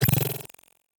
Hi Tech Alert 3.wav